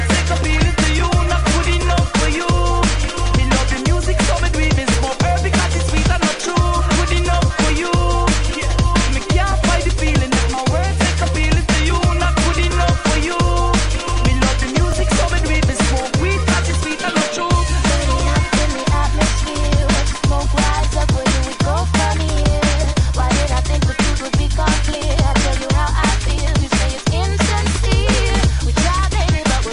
TOP >Vinyl >Drum & Bass / Jungle
TOP > Vocal Track